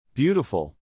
“beautiful” /BEAU-ti-ful/ and the word stress falls on the first one /BEAU/.